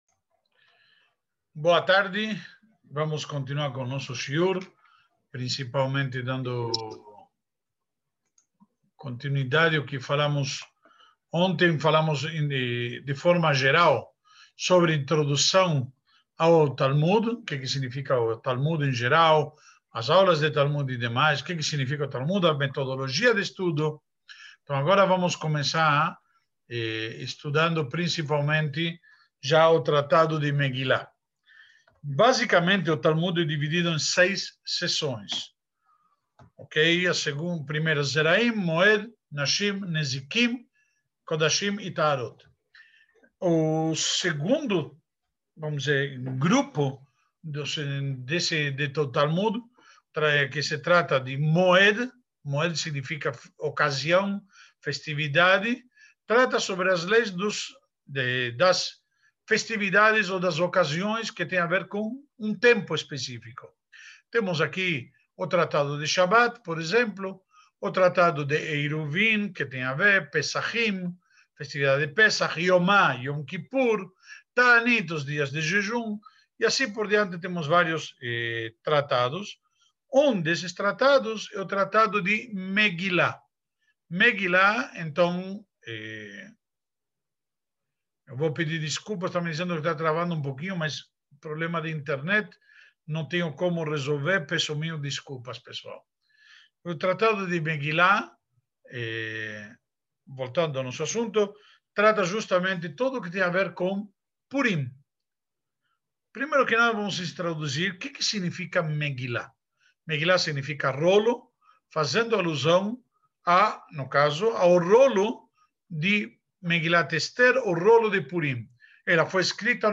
Projeto Mishná 5781- aula 1